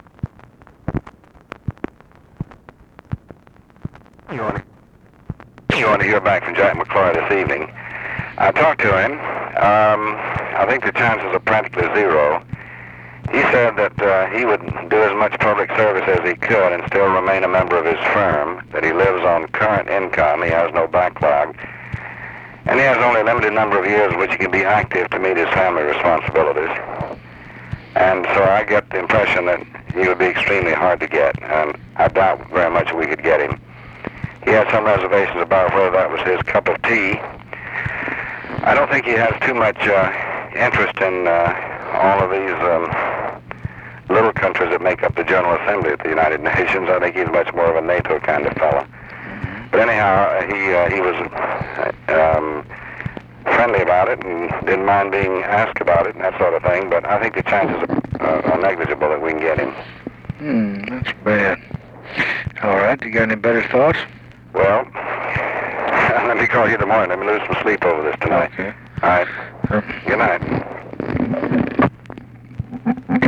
Conversation with DEAN RUSK, December 9, 1967
Secret White House Tapes